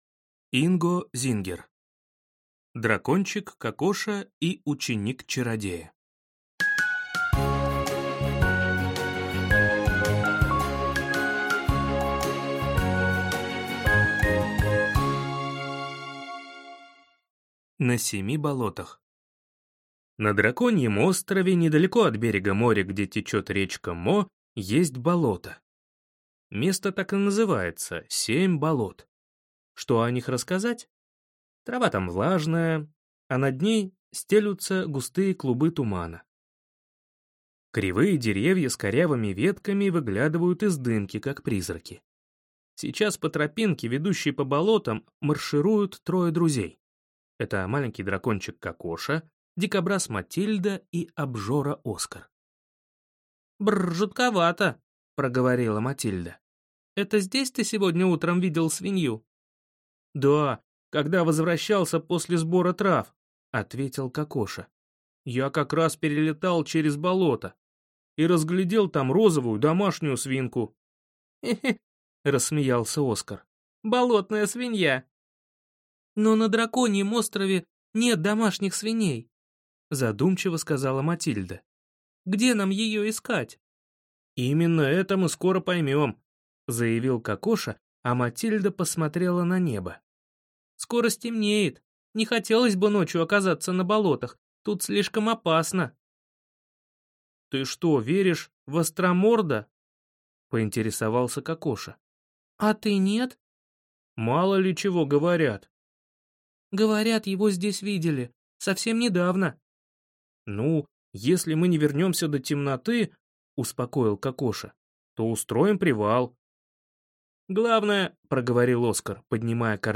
Аудиокнига Дракончик Кокоша и ученик чародея | Библиотека аудиокниг